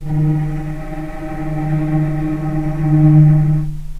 healing-soundscapes/Sound Banks/HSS_OP_Pack/Strings/cello/ord/vc-D#3-pp.AIF at ae2f2fe41e2fc4dd57af0702df0fa403f34382e7
vc-D#3-pp.AIF